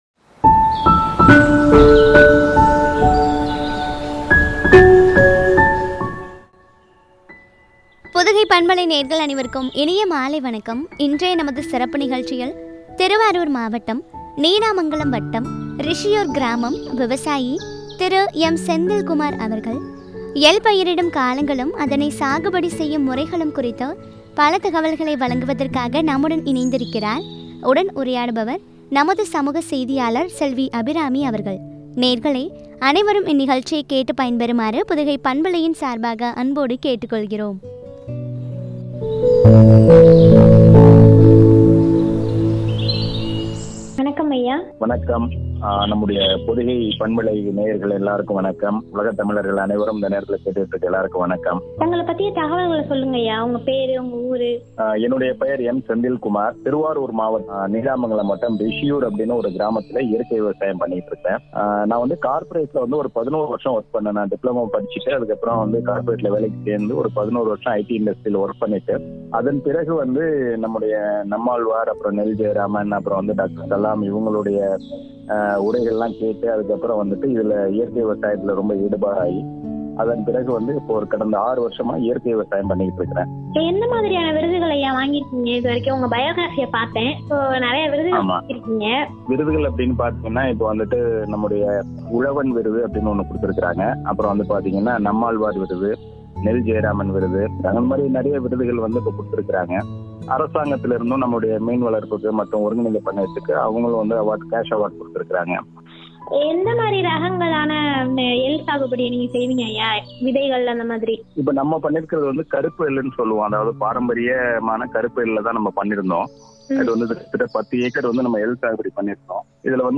சாகுபடி முறைகளும் பற்றிய உரையாடல்.